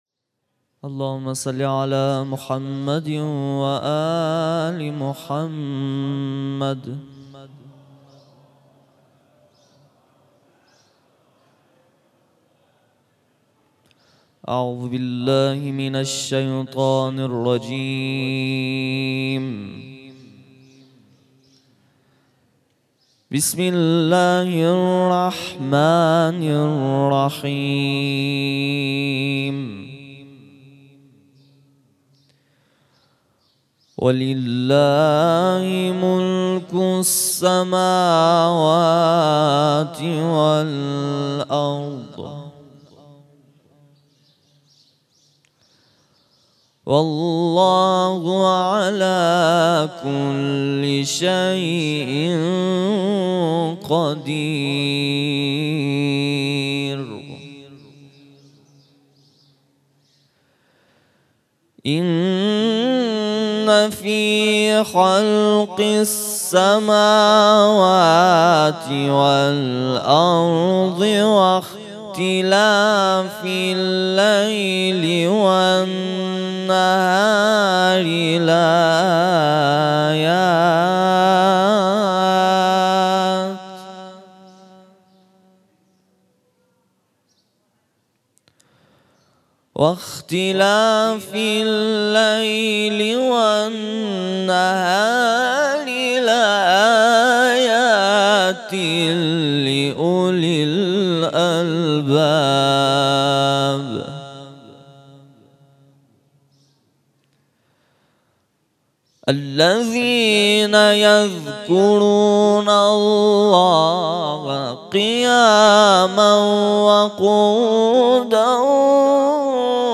صوت | تلاوت